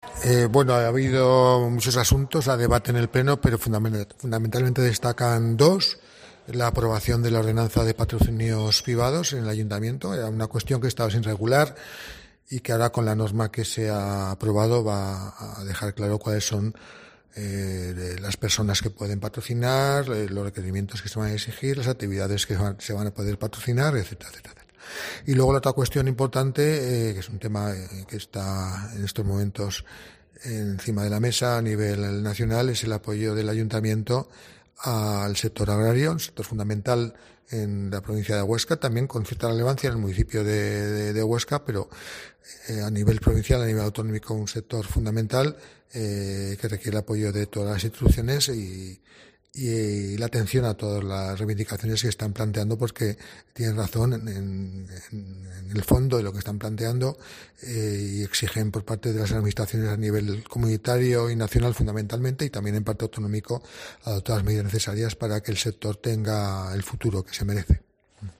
Ricardo Oliván, teniente de alcalde del Ayuntamiento de Huesca